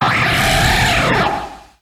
Catégorie:Cri Pokémon (Soleil et Lune) Catégorie:Cri de Tokorico